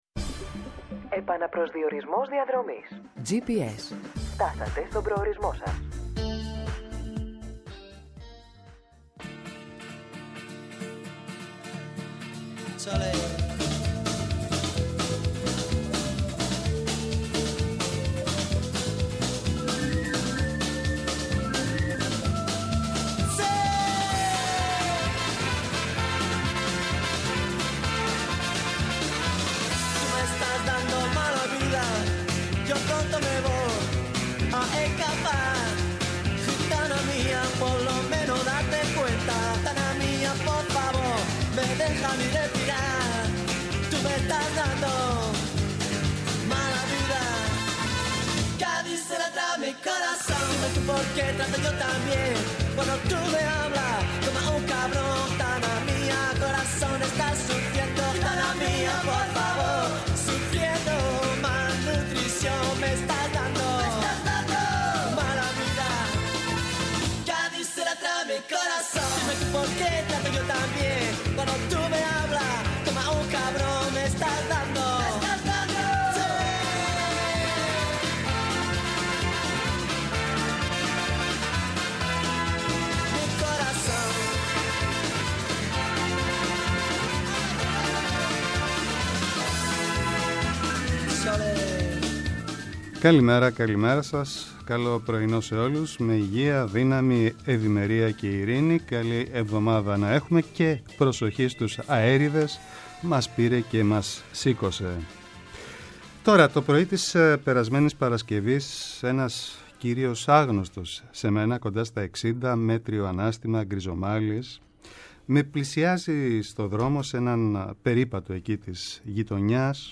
‘Eνα καθημερινό ραντεβού με τον παλμό της επικαιρότητας, αναδεικνύοντας το κοινωνικό στίγμα της ημέρας και τις αγωνίες των ακροατών μέσα από αποκαλυπτικές συνεντεύξεις και πλούσιο ρεπορτάζ επιχειρεί να δώσει η εκπομπή Gps